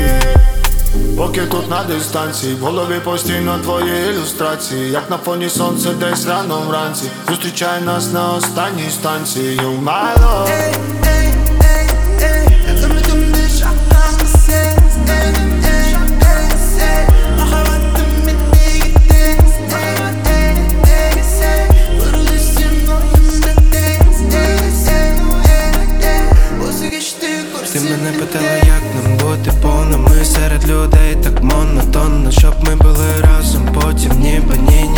Жанр: Рэп и хип-хоп / Украинские
# Rap